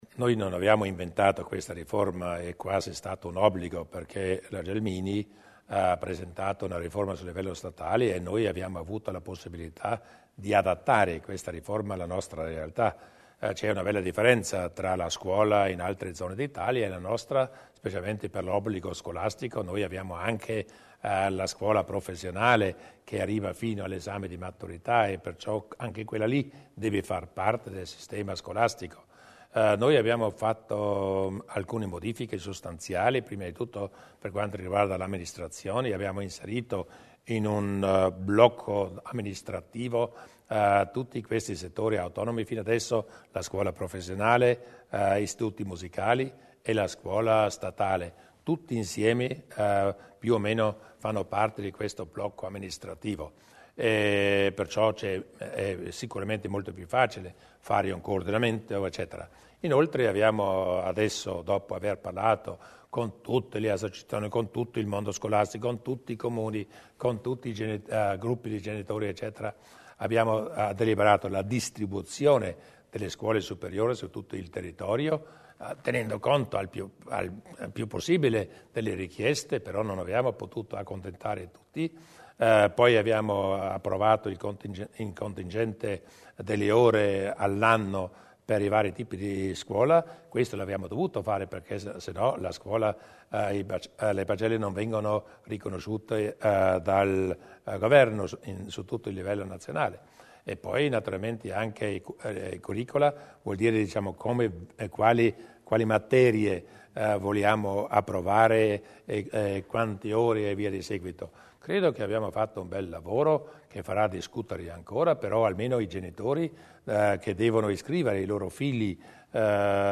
Il Presidente Durnwalder sulla riforma scolastica